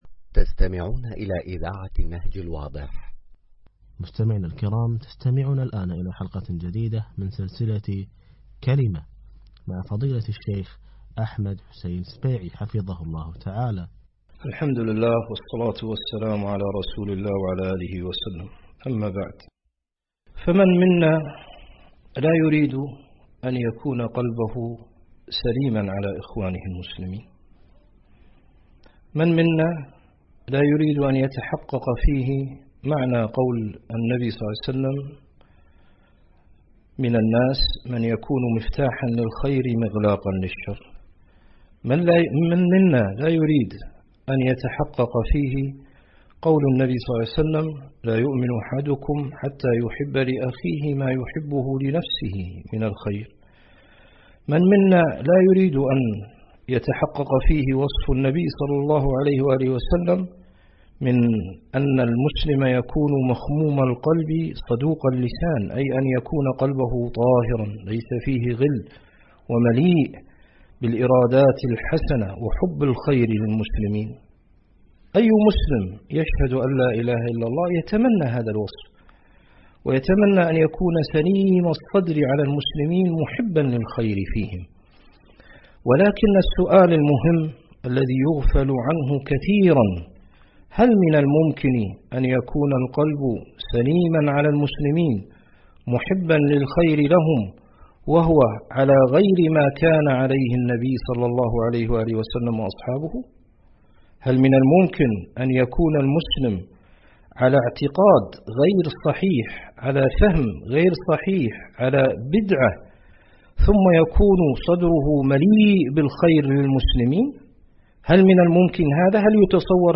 الدروس المنقولة عبر إذاعة النهج الواضح